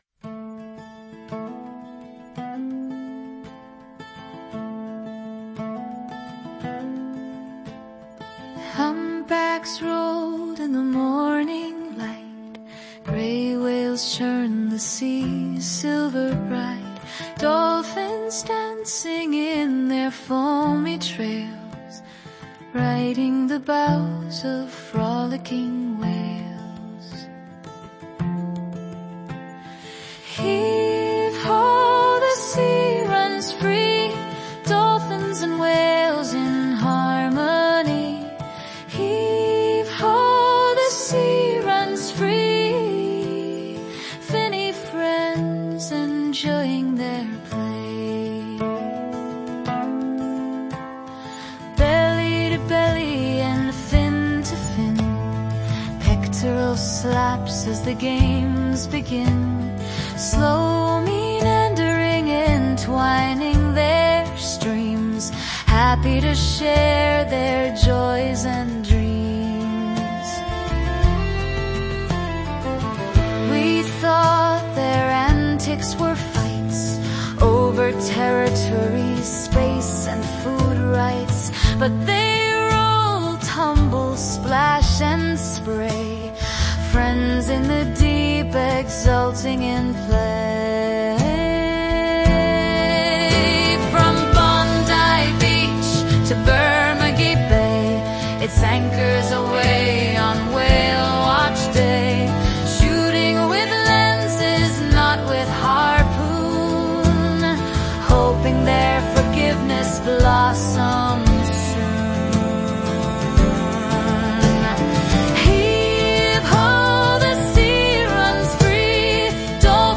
[ hear the song with whale songs at the end]